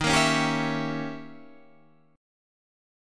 D#7b9 chord